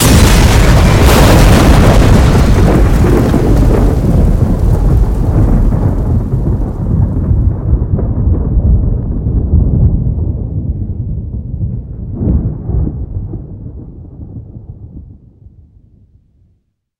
rbmk_explosion.mp3